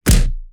face_hit_Large_29.wav